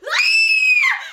دانلود صدای جیغ 4 از ساعد نیوز با لینک مستقیم و کیفیت بالا
جلوه های صوتی
برچسب: دانلود آهنگ های افکت صوتی انسان و موجودات زنده دانلود آلبوم صدای جیغ ترسناک از افکت صوتی انسان و موجودات زنده